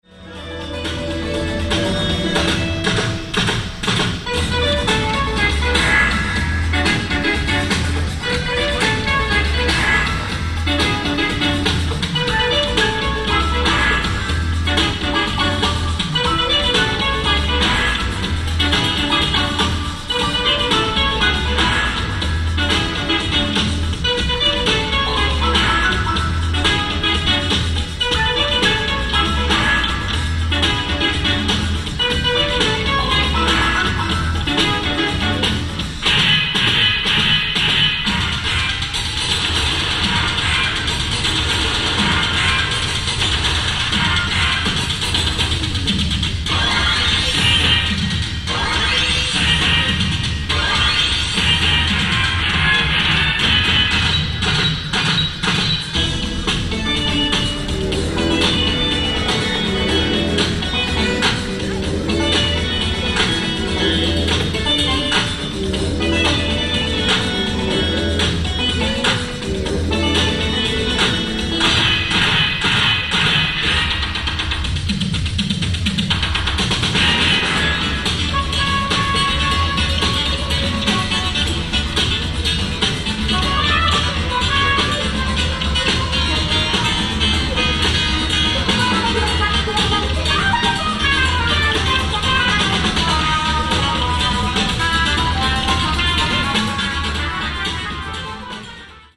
ライブ・アット・ユニバーサル・アンフィシアター、ロサンゼルス　09/29/1984
極上オーディエンス音源盤！！
※試聴用に実際より音質を落としています。